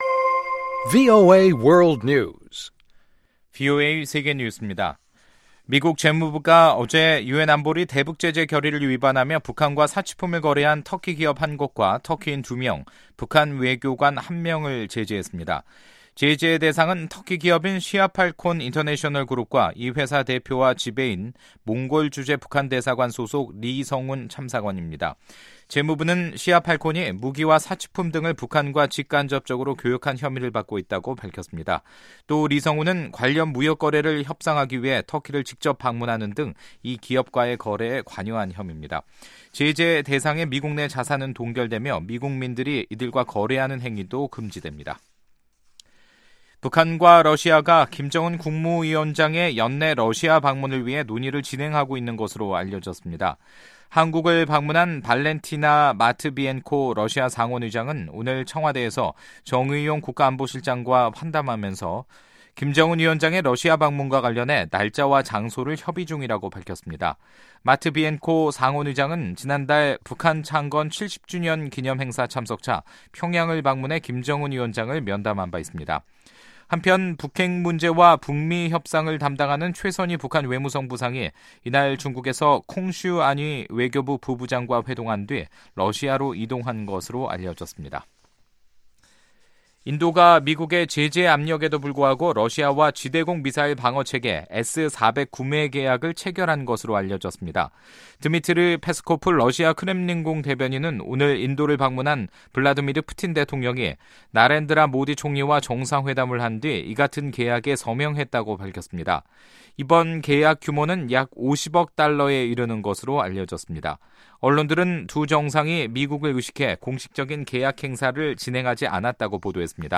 VOA 한국어 간판 뉴스 프로그램 '뉴스 투데이', 2018년 10월 5일 3부 방송입니다. 미 재무부 해외 자산 통제실은 북한 관련 제재를 위반한 북한 외교관과 터키인을 제재 대상에 추가했습니다. 미 국무부는 북한 주민들의 안녕은 우려되지만 현재 북한에 대한 인도주의 지원 계획은 없다고 밝혔습니다.